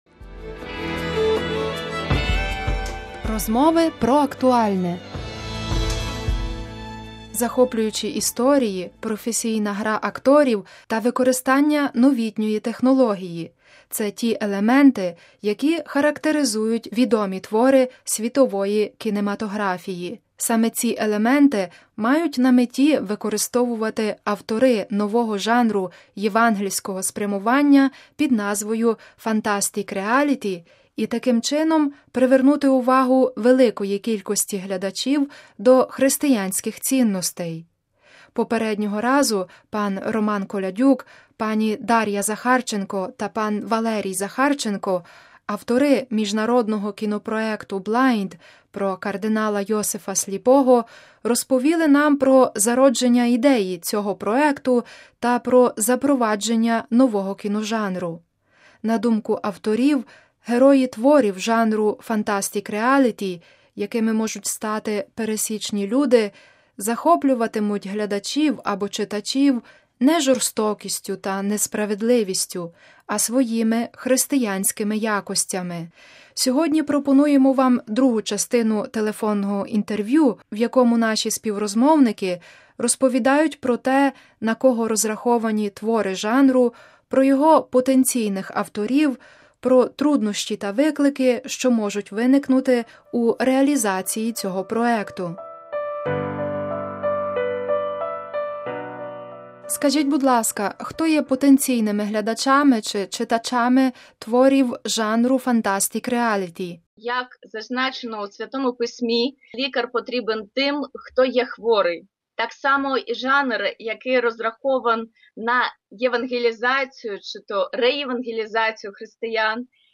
Сьогодні пропонуємо вам другу частину телефонного інтерв’ю, в якому співрозмовники розповідають про те, на кого розраховані твори жанру, про його потенційних авторів, про труднощі та виклики, що можуть виникнути у реалізації цього проекту: